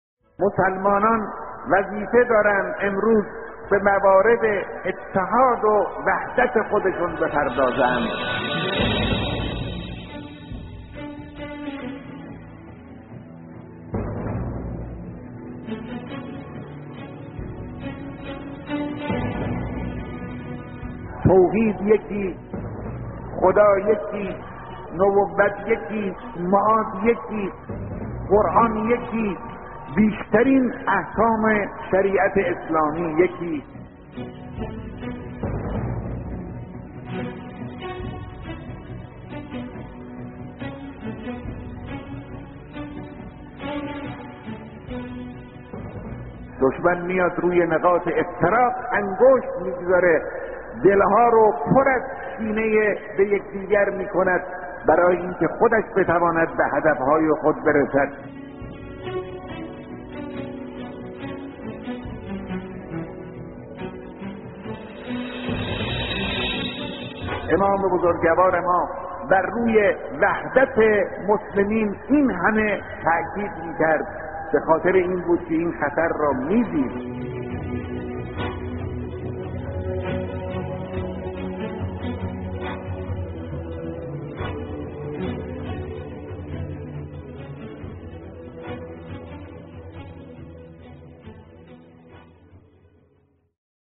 کلیپ صوتی از بیانات رهبر انقلاب در مورد وحدت